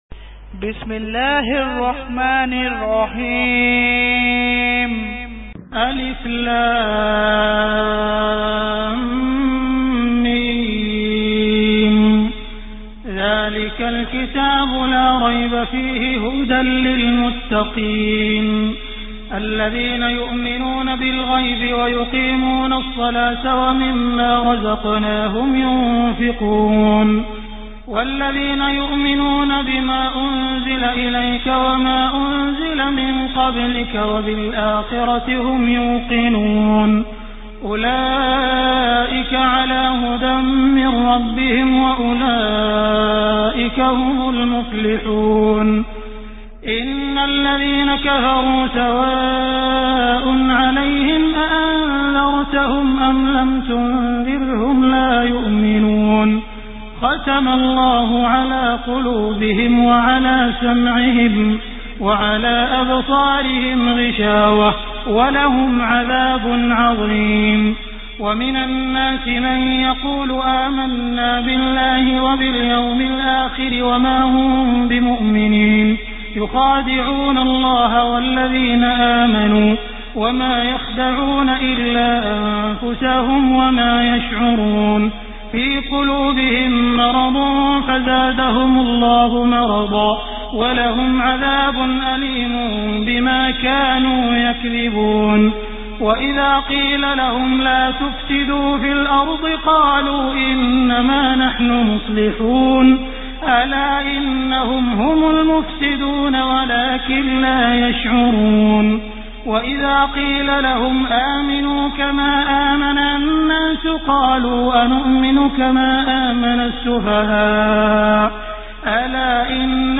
Surah Baqarah Beautiful Recitation MP3 Download By Abdur Rahman Al Sudais in best audio quality.